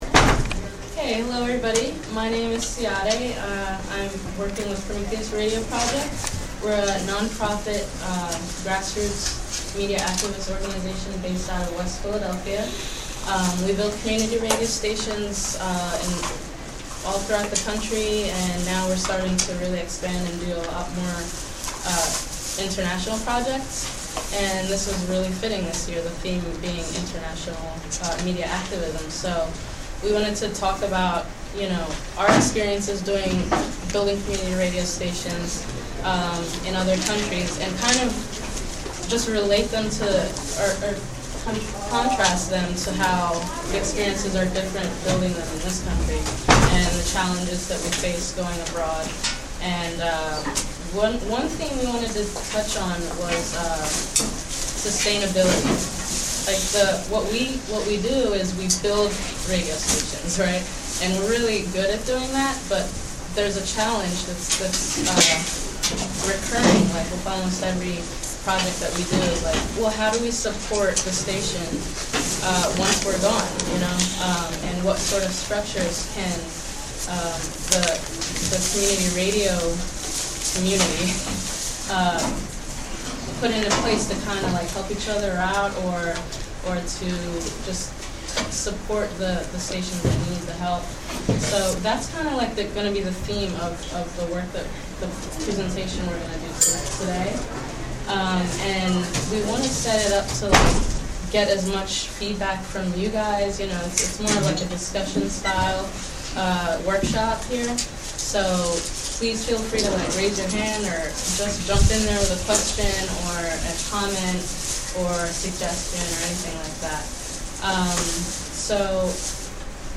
Prometheus Radio Project workshop at 2006 NYC Grassroots Media Conference. (Audio)
(Audio) events NYC Grassroots Media Conference 2008 : Mar 02, 2008: 12am- 11:59 pm Hunter College artists Prometheus Radio Project Recorded by free103point9 at New School in Manhattan.